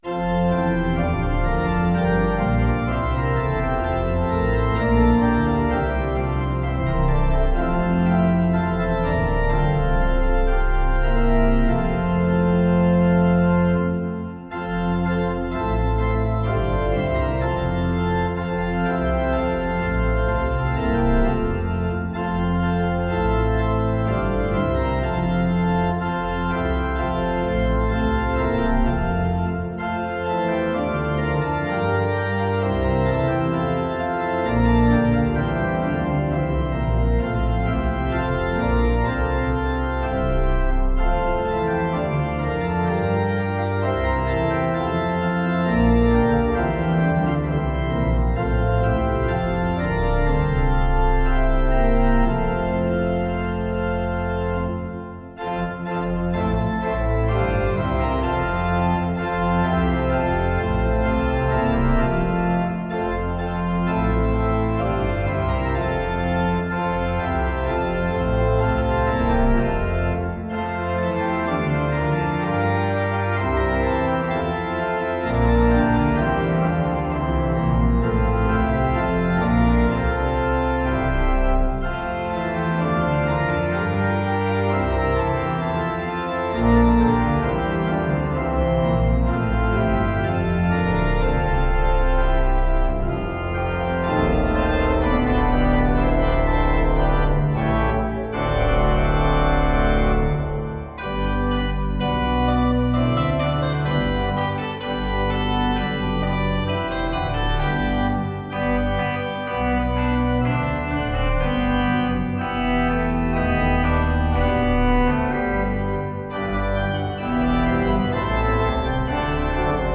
organ solo
This setting features a hymn introduction, then the hymn itself, followed by a modulation and a final stanza with festive reharmonization.